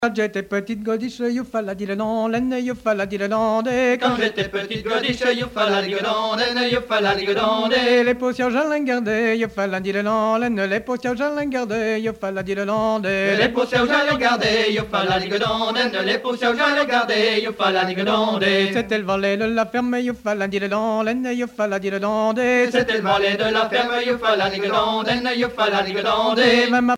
Fonction d'après l'analyste danse : ronde ;
Genre laisse
Pièce musicale éditée